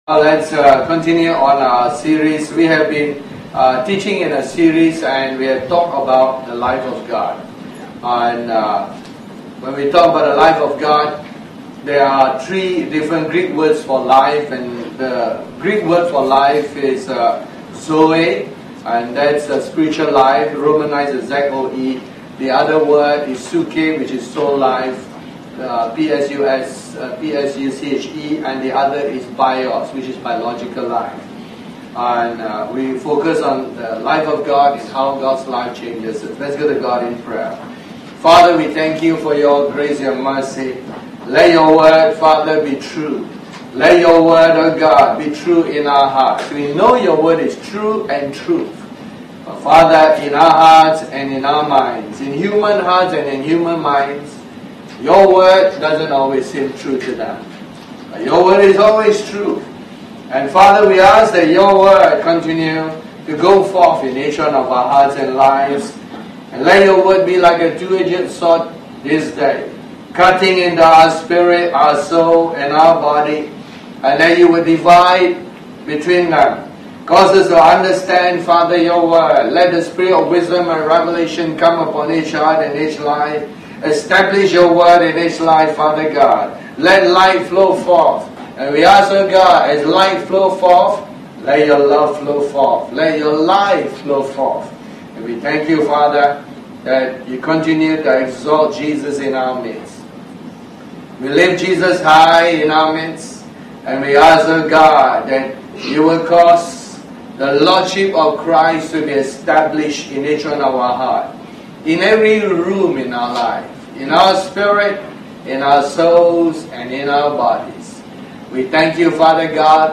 Series: The Life of God Tagged with Sunday Service